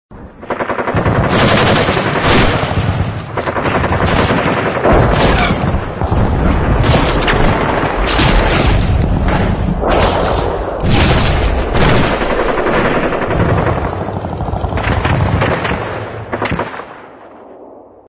Âm thanh Chiến Tranh tiếng Súng, Pháo nổ